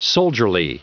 Prononciation du mot soldierly en anglais (fichier audio)
Prononciation du mot : soldierly